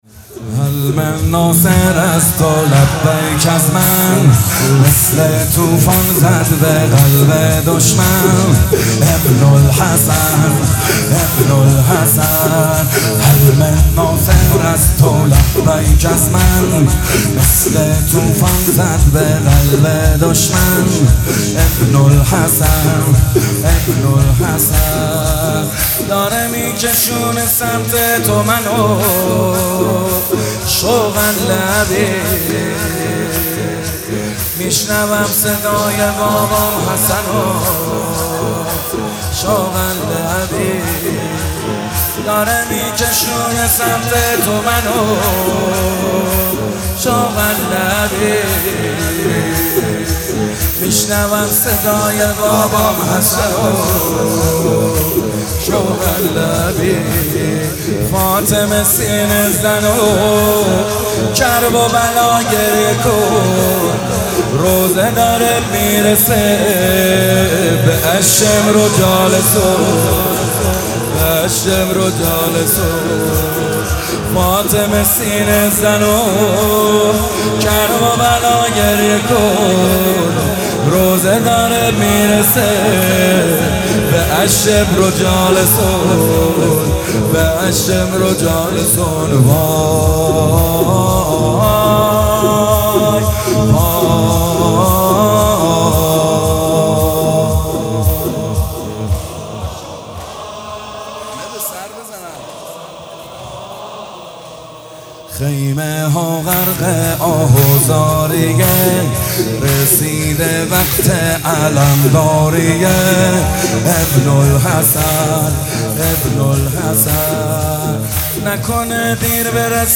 شب پنجم محرم ۱۴۰۱؛
مراسم عزاداری دهه اول محرم سال ۱۴۰۱